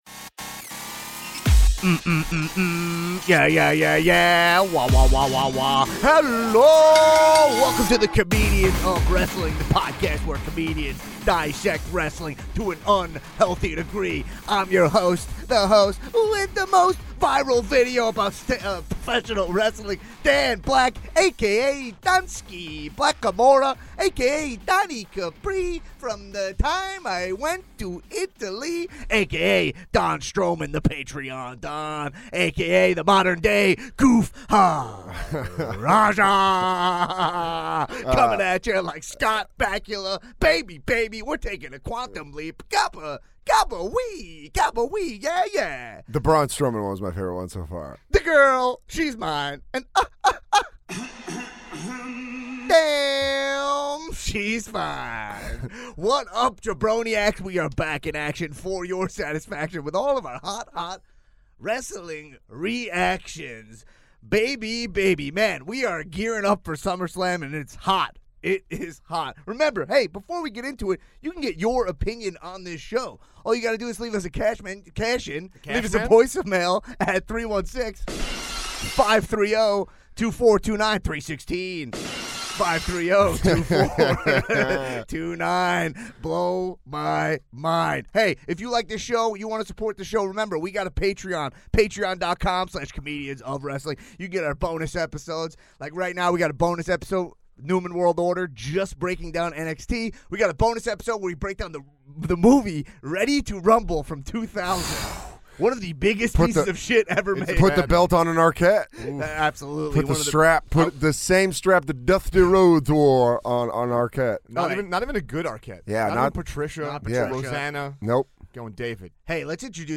comedian and king of the Macho Man impression